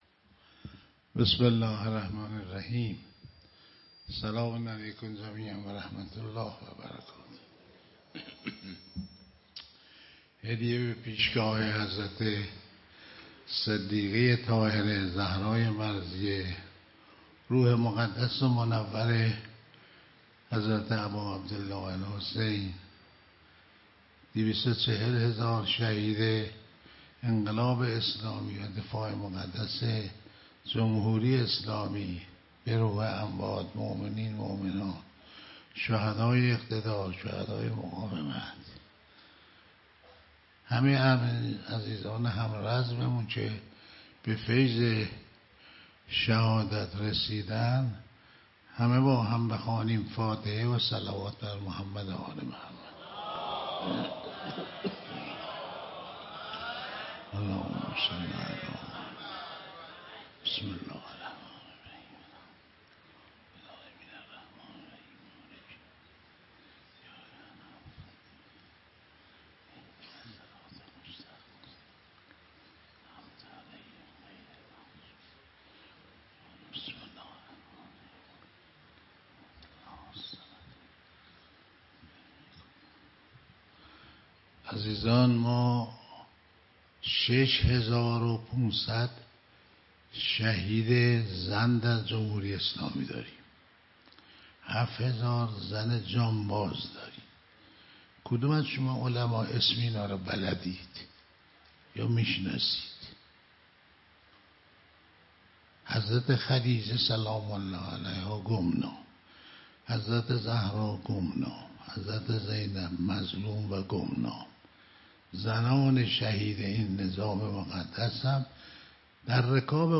سخنرانی سردار مرتضی قربانی در "یادواره مبلغان مجاهد"